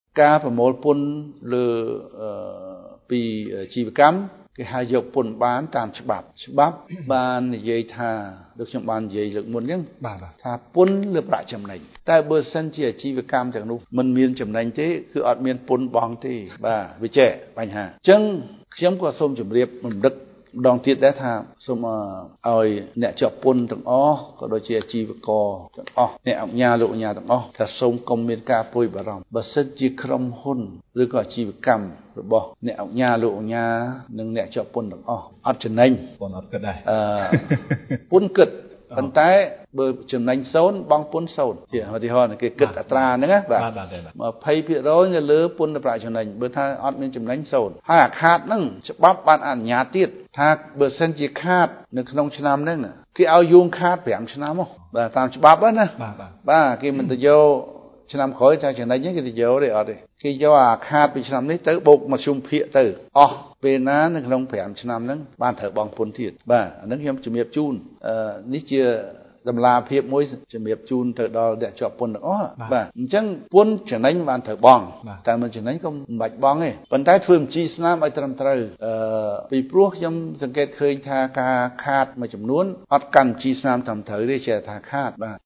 លោក គង់ វិបុល អគ្គនាយក នៃអគ្គនាយកដ្ឋានពន្ធដារ
សំឡេងលោកគង់ វិបុល៖